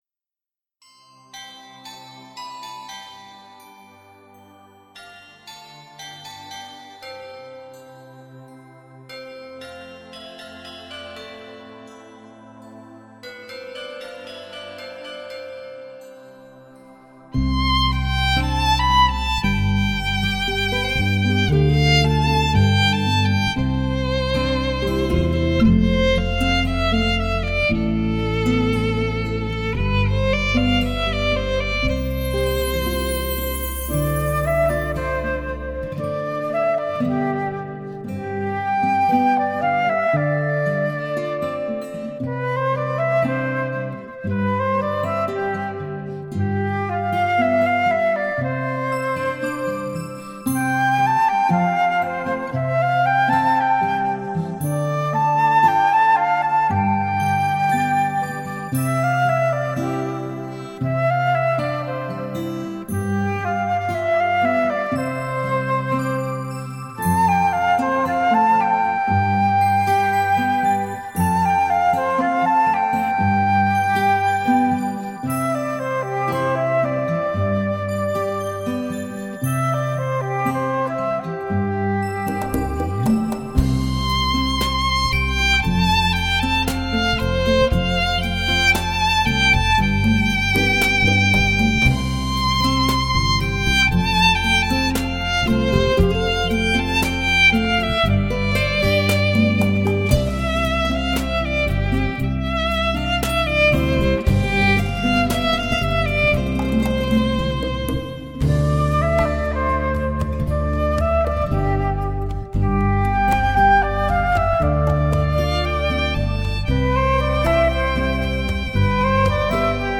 纯音乐 纯发烧